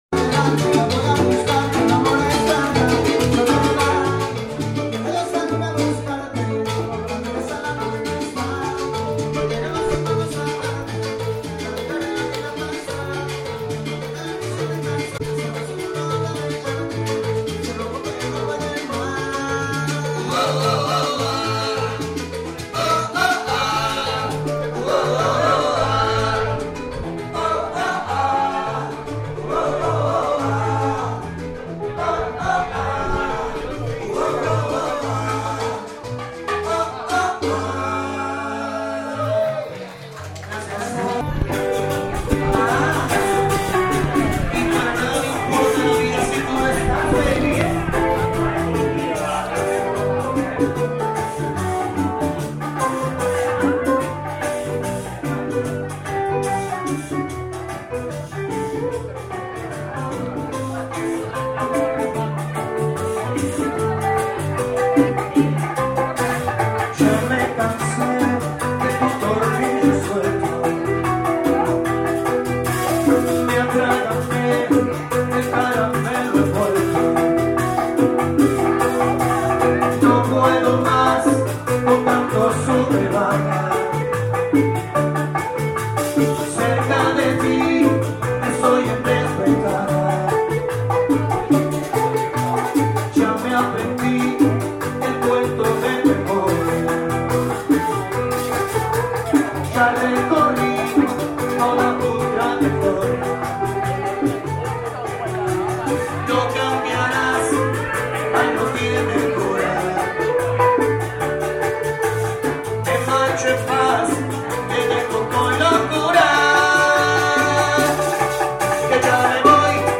何とリズムが良い音楽なんだろう
「サルサ」「ルンバ」？
本当にリズムがいい　自然に体が動く
メキシコより数段にリズムがいい